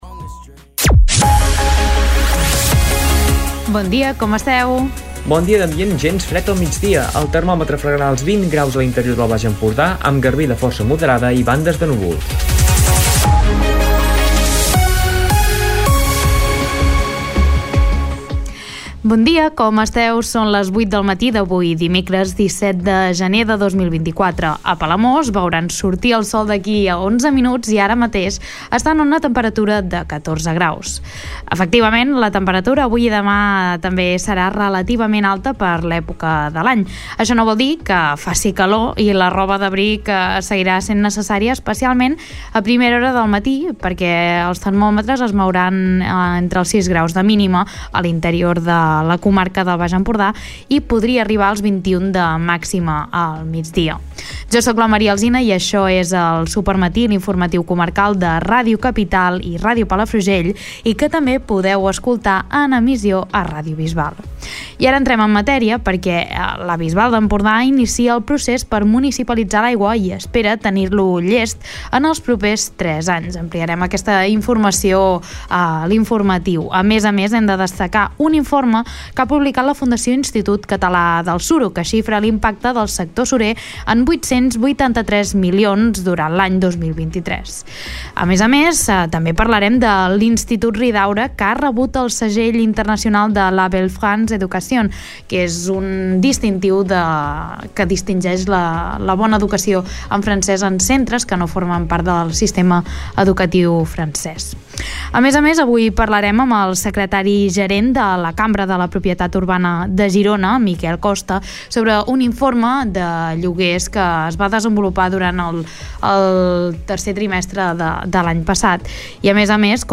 Escolta l'informatiu d'aquest dimecres